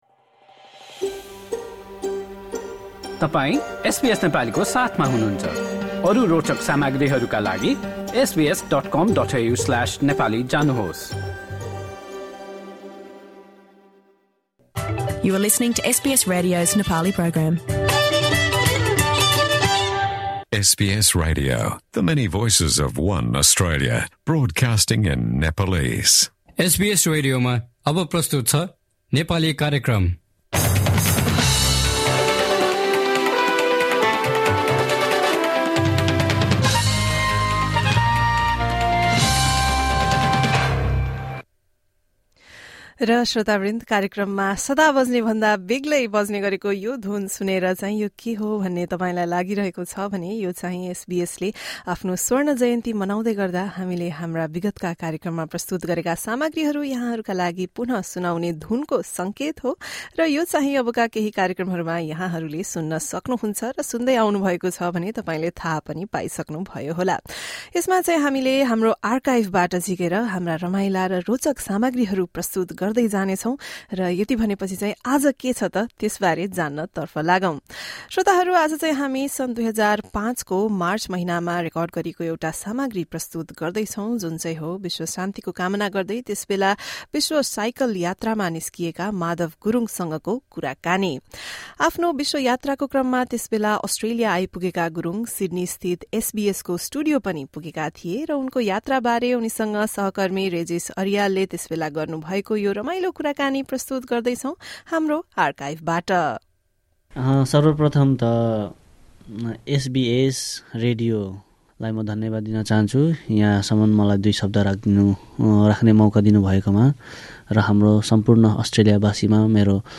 यो कुराकानी शुक्रवार, १८ मार्च २००५ मा सिड्नीस्थित एसबीएसको स्टुडियोमा रेकर्ड गरिएको थियो।